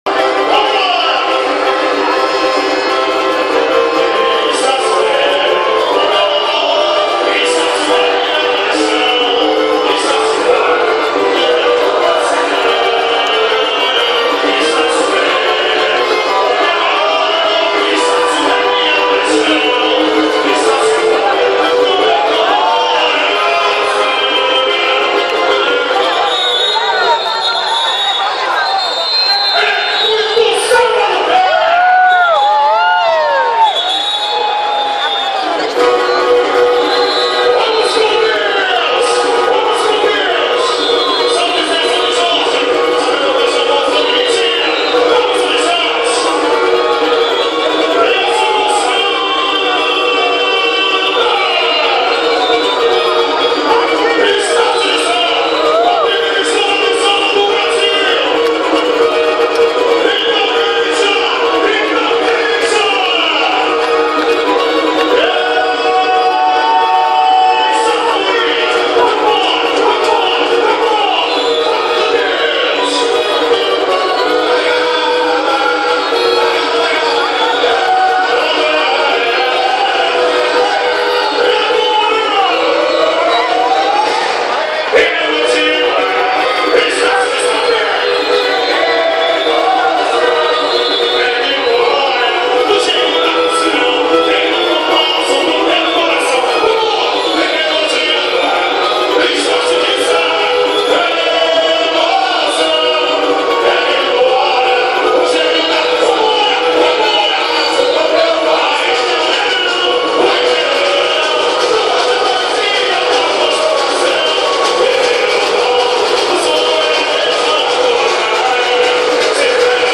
Animada, com bons carros e fantasias, pecou em dois aspectos: alguns erros de bateria e o samba enredo, aquém das tradições da escola.